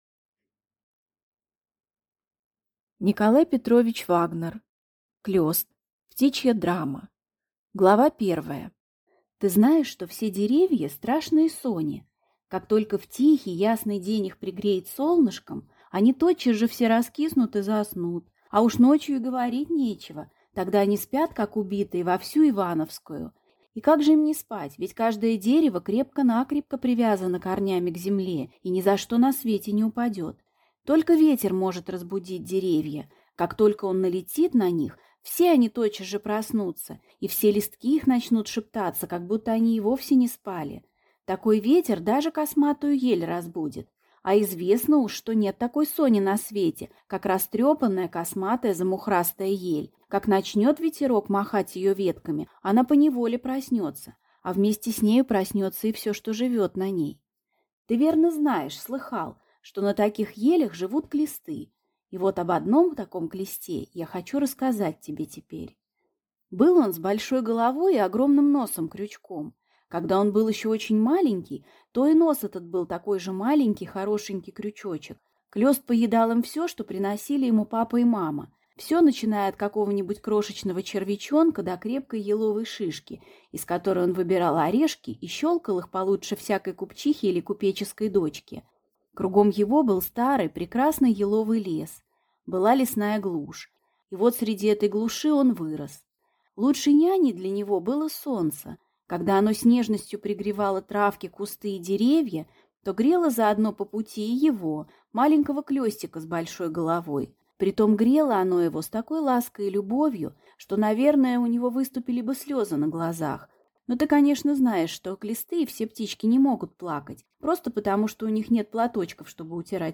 Aудиокнига Клёст